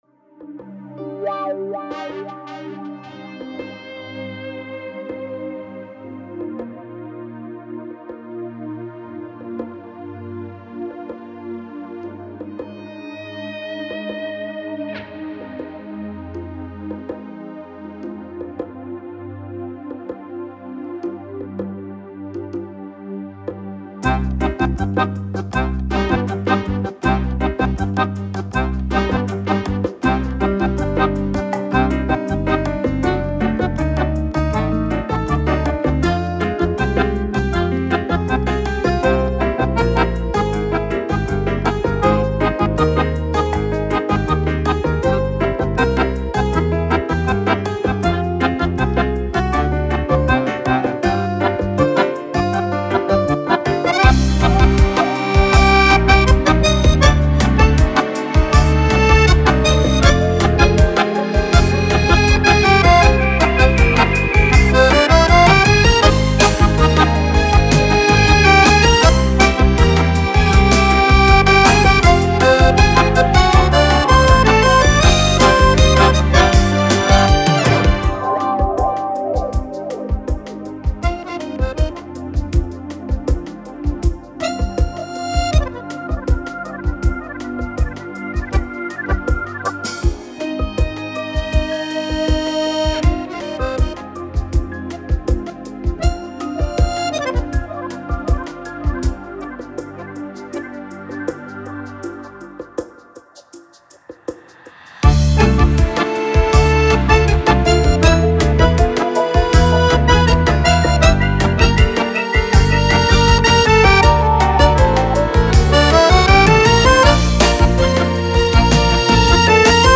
instrumentalnaya-muzyika-(bayan-i-akkordeon)---jizn.mp3